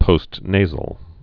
(pōst-nāzəl)